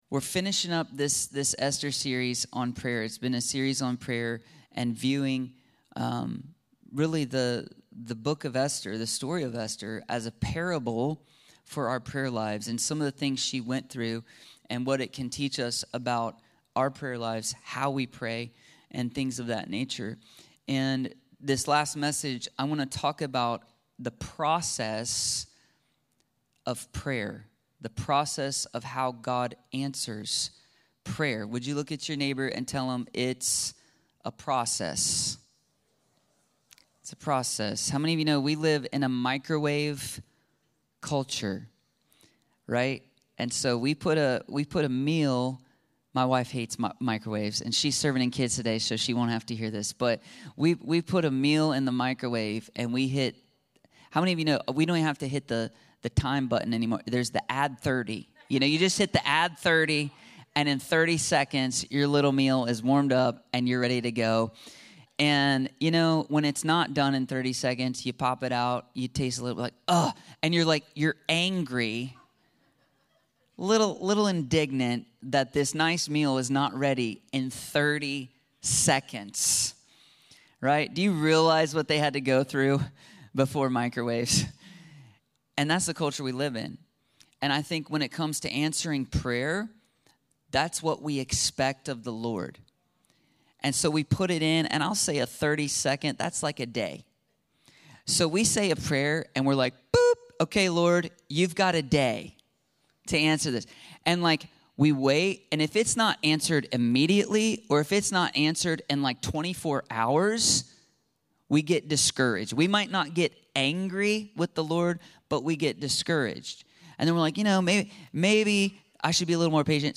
How God Answers Our Prayers - Storm The Throne ~ Free People Church: AUDIO Sermons Podcast